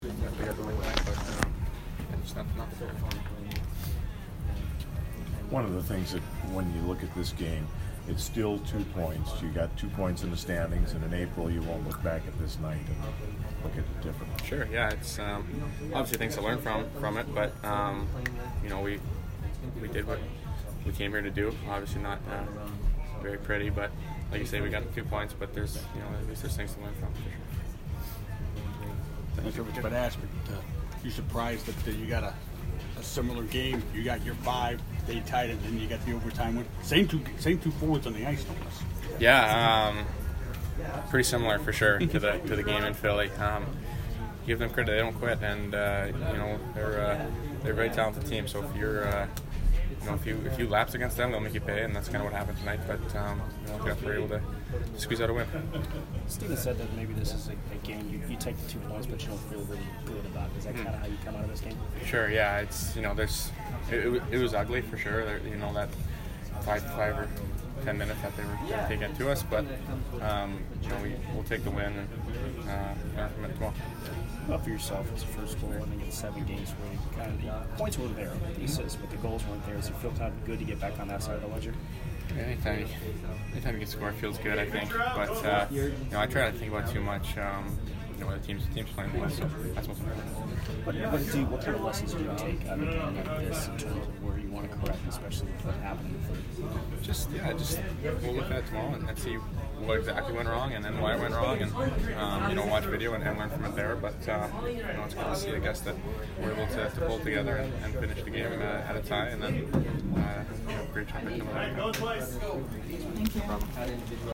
Brayden Point post-game 12/27